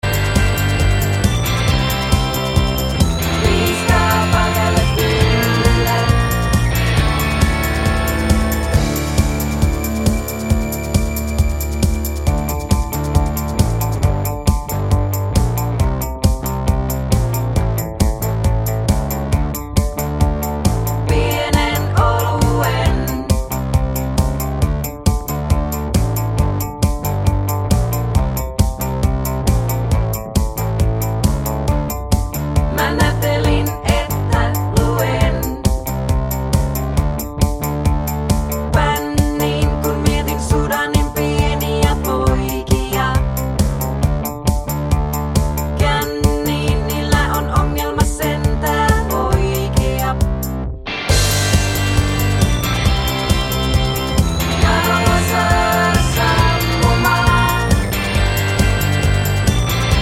no Backing Vocals